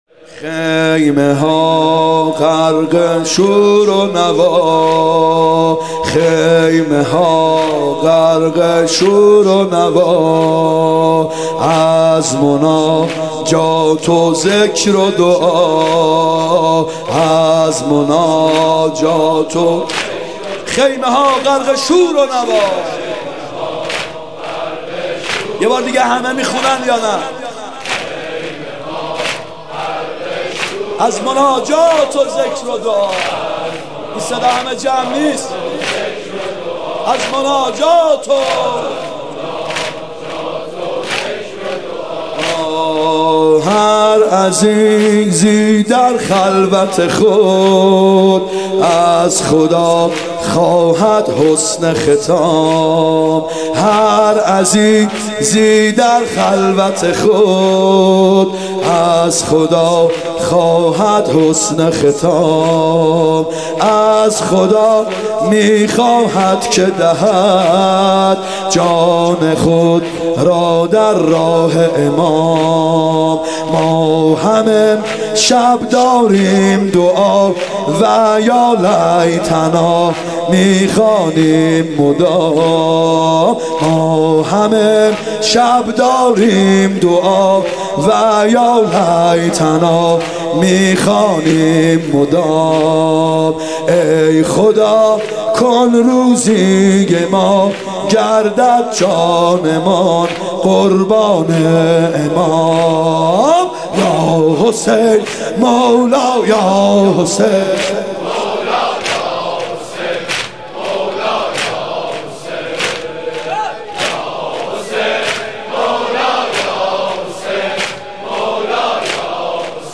محرم 94(هیات یا مهدی عج)